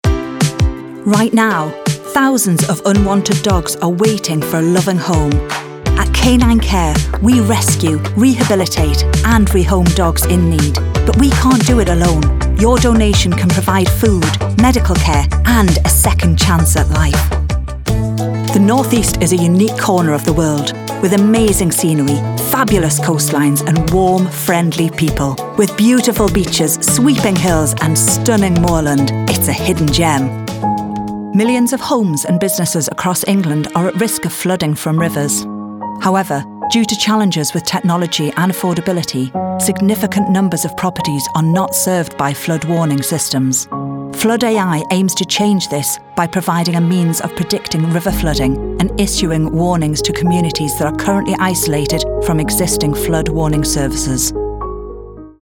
Northumbrian
Range 40s and over
A warm and natural quality.